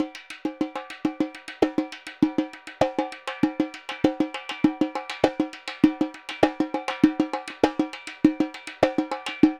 Repique 2_Samba 100_1.wav